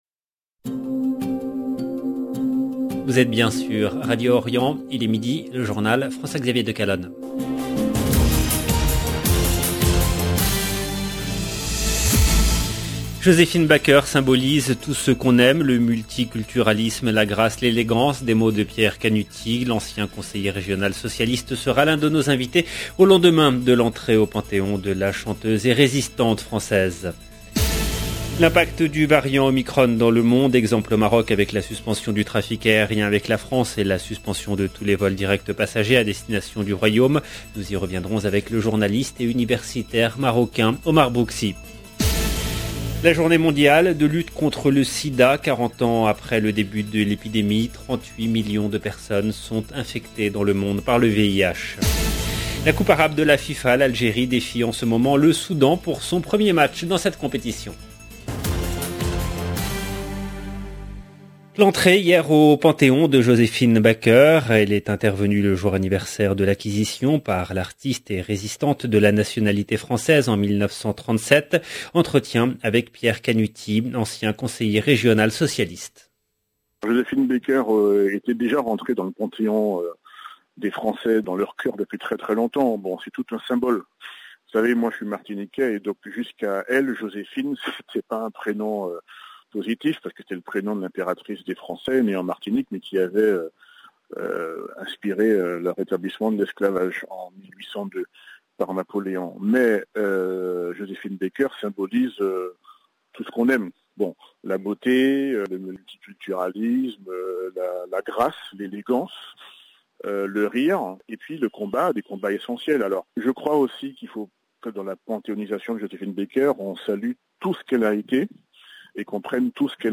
LE JOURNAL EN LANGUE FRANCAISE DE MIDI DU 1/12/21